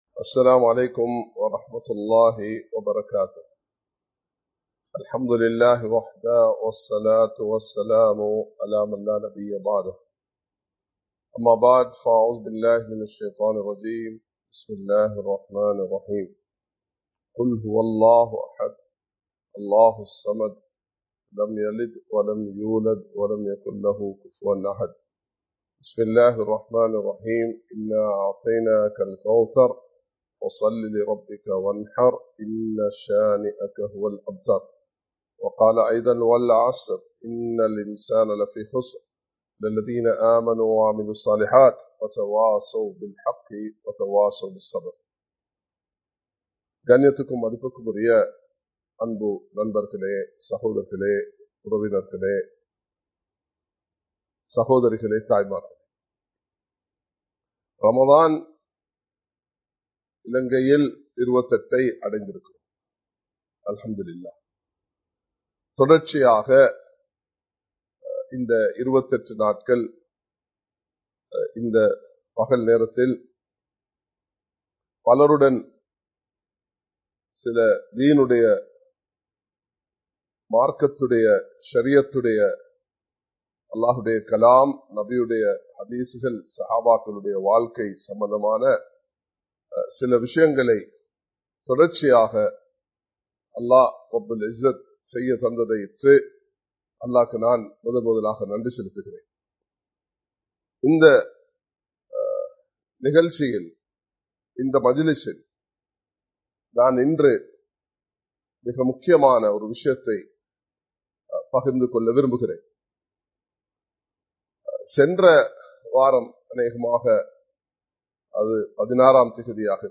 Live Stream